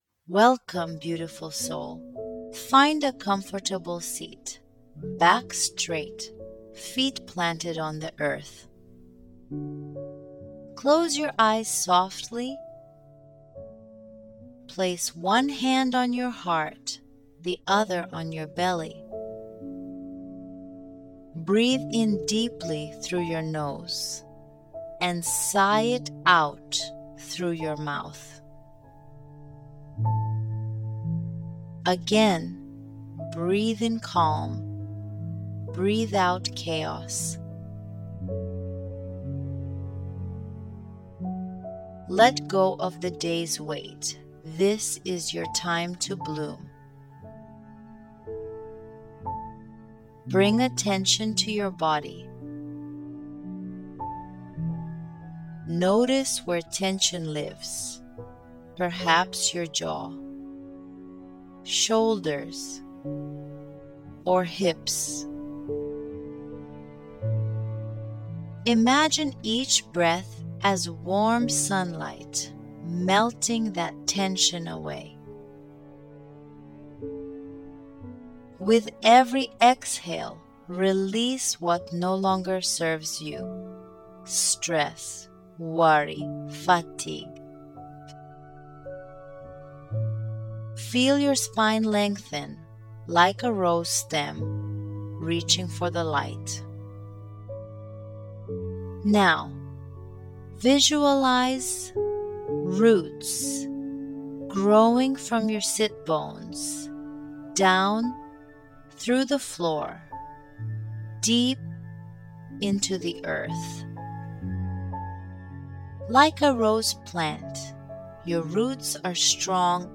Guided Meditation
Root_Like_a_Rose_Guided_Meditation.mp3